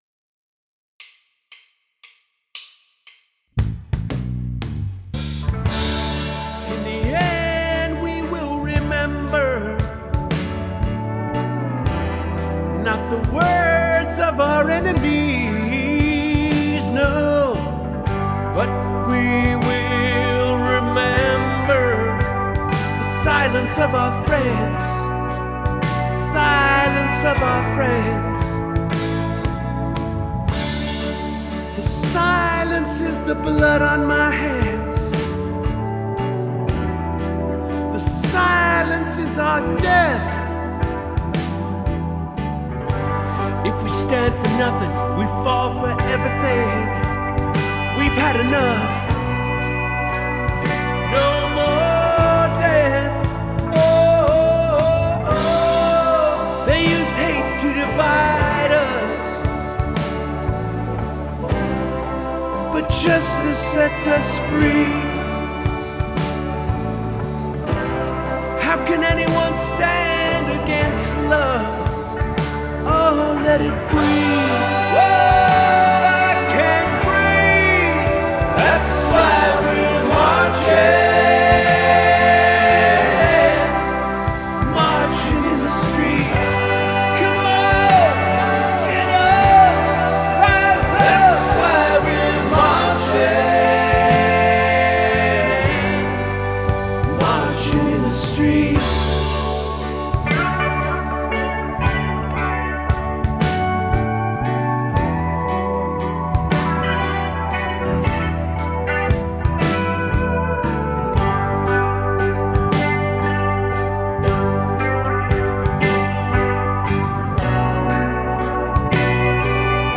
Pedal Steel